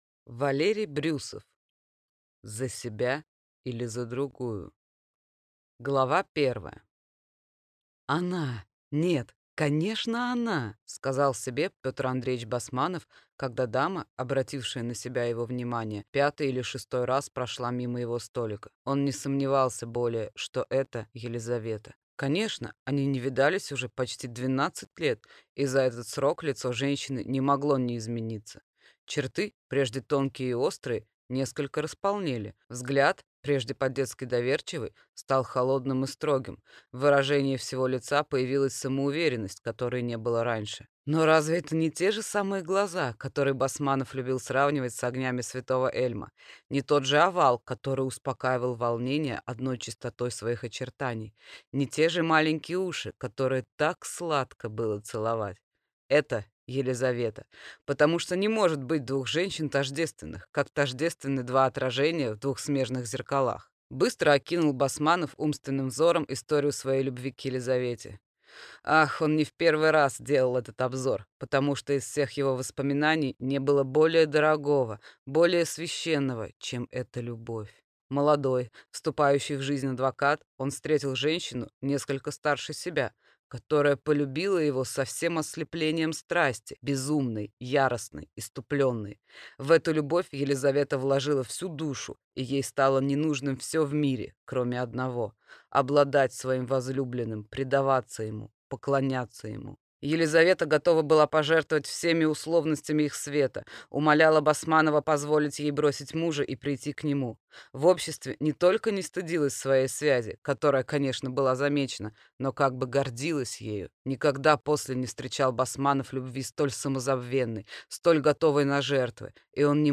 Aудиокнига За себя или за другую?